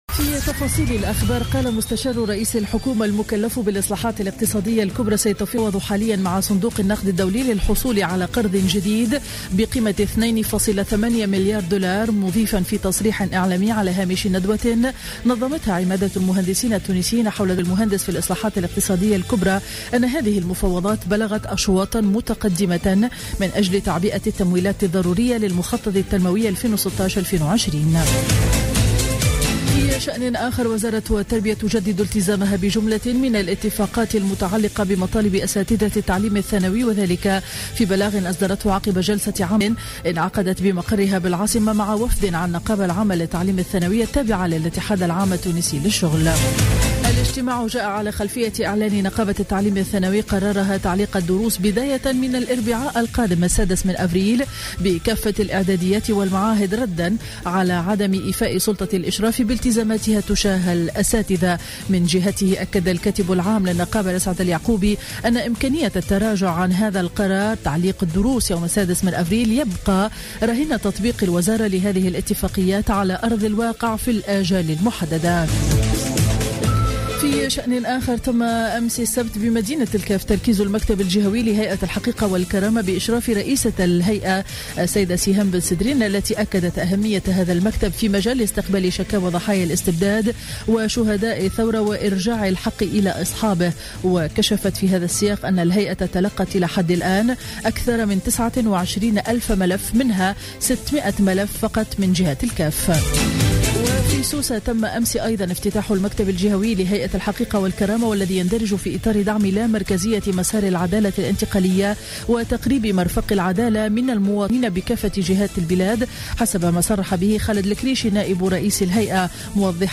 نشرة أخبار السابعة صباحا ليوم الأحد 3 أفريل 2016